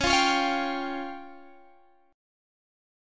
C#Mb5 chord